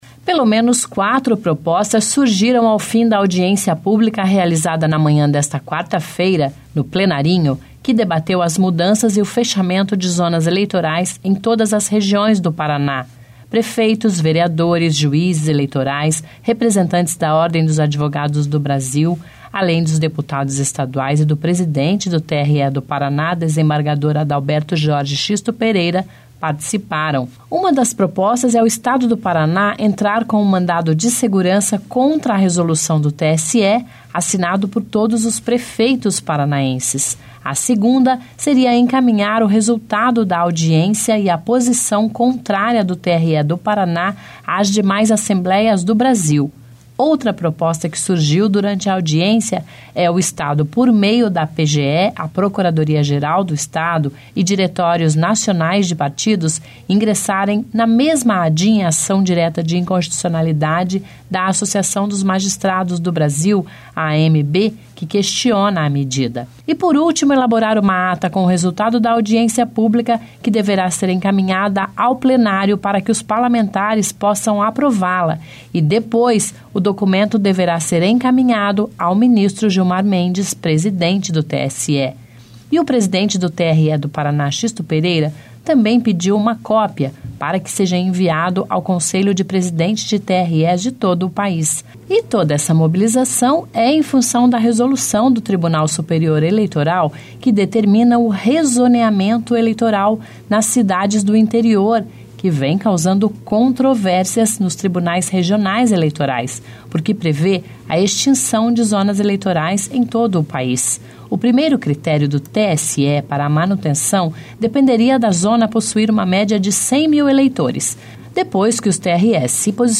(Descrição do áudio)) Pelo menos quatro propostas surgiram ao fim da audiência pública realizada na manhã desta quarta-feira (21), no Plenrinho da Assembleia Legislativa, que debateu as mudanças e o fechamento de zonas eleitorais em todas as regiões do Paraná.